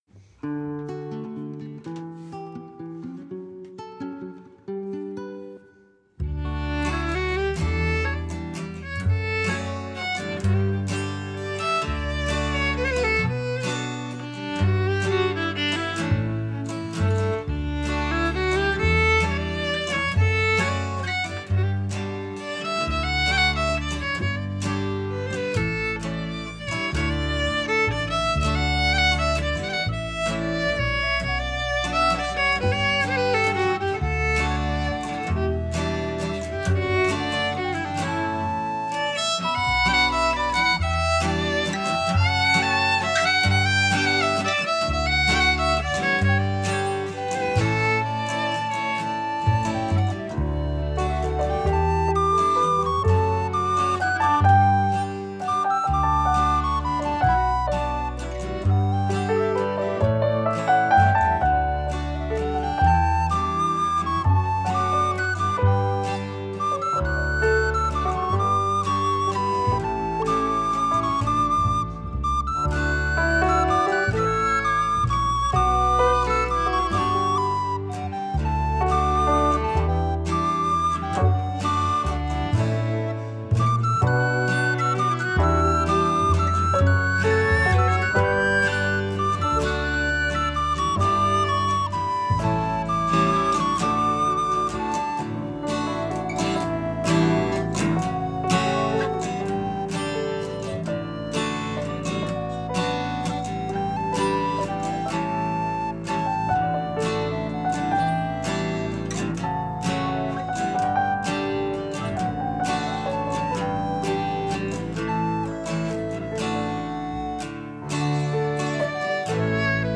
We got a new waltz track for it
Here is an MP3 file with just the music: